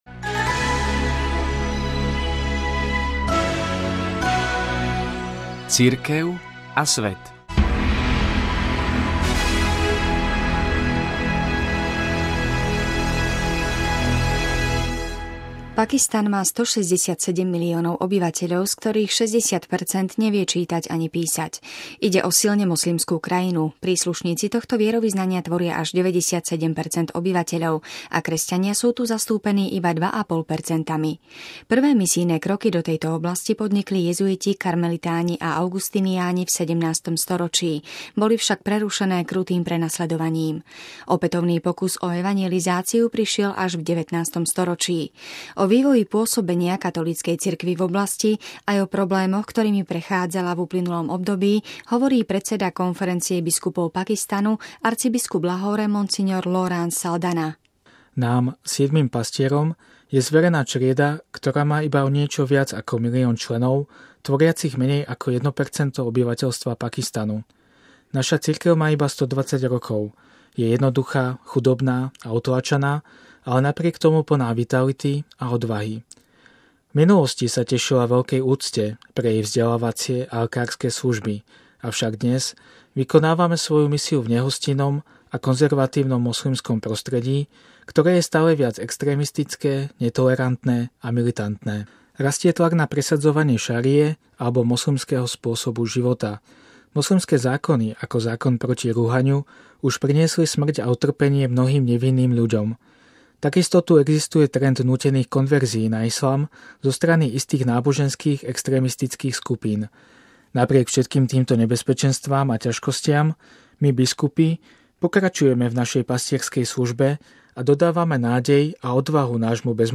O vývoji pôsobenia Katolíckej cirkvi v oblasti, aj o problémoch, ktorými prechádzala v uplynulom období, hovorí predseda Konferencie biskupov Pakistanu arcibiskup Lahore Mons. Lawrence J. Saldanha: